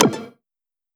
button-select.wav